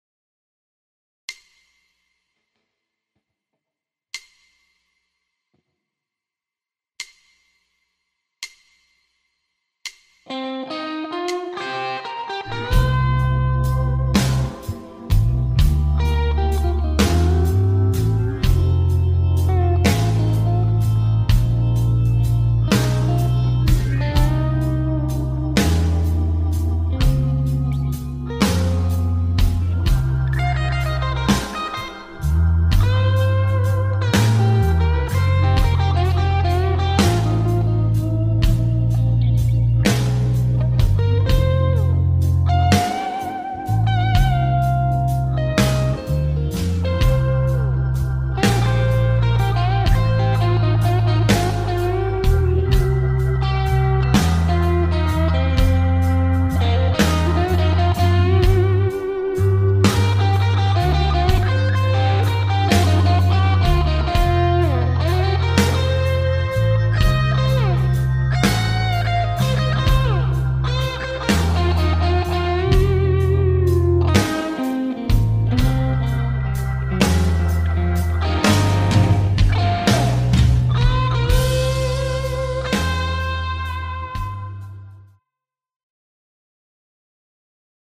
Vedetääs tasaluvun 700 kunniaksi hidasta bluesia.
-kun osallistut, soita soolo annetun taustan päälle ja pistä linkki tähän threadiin.